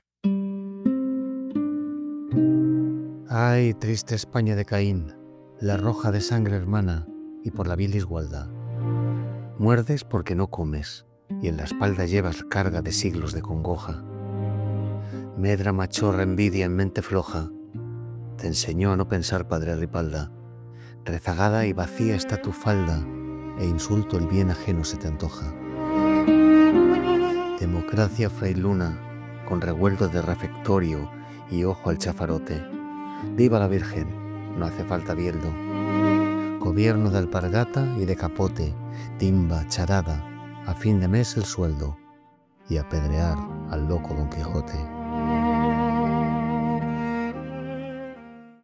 Unamuno.-Ay-triste-Espana-enhanced_music.mp3